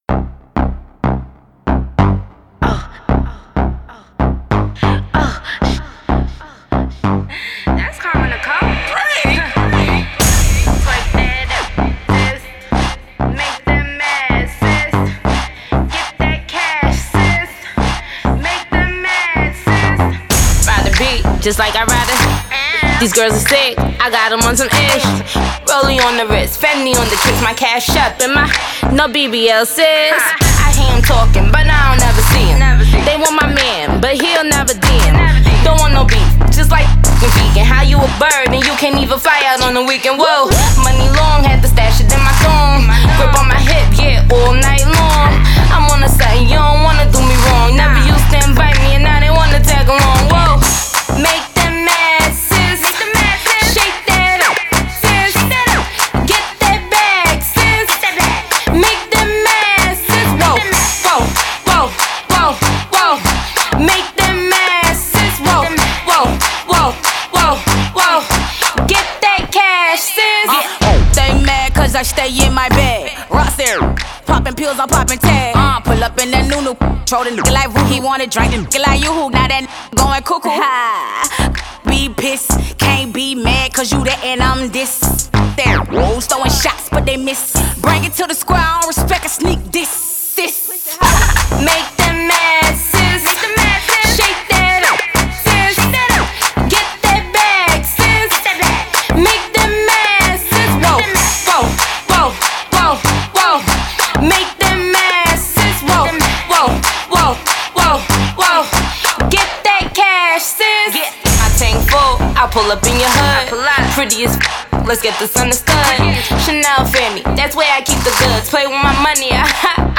hard hitting bars with an “in your face” style
saucy, yet explosive racy lyrics